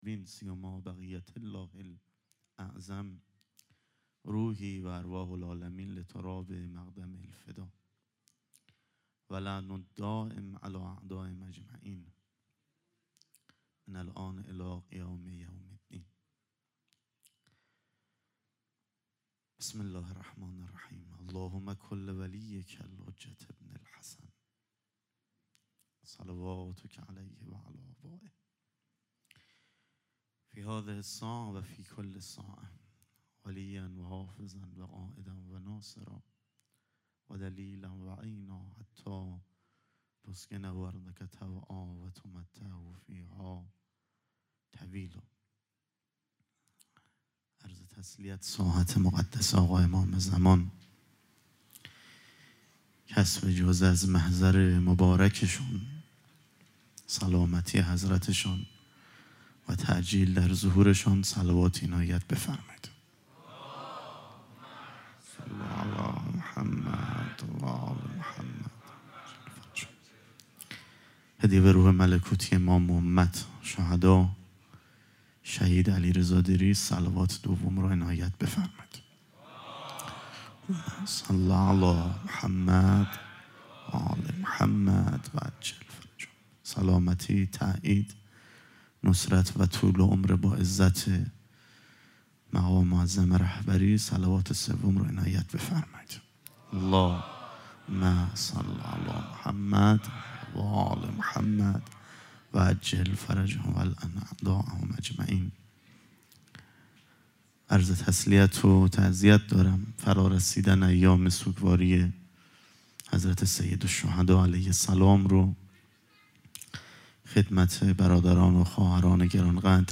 4 0 سخنرانی